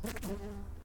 bees.2.ogg